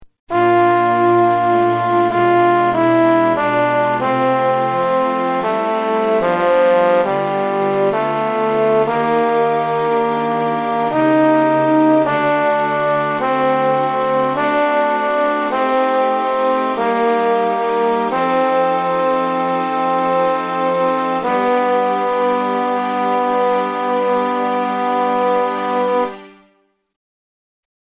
Key written in: G Minor
Type: Other male